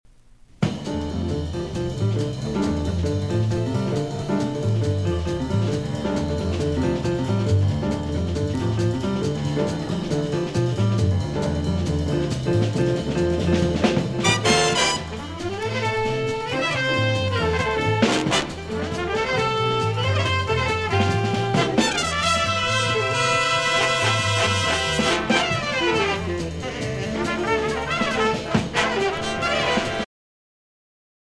alto saxophone
flugelhorn
tenor saxophone
trombone
Jazz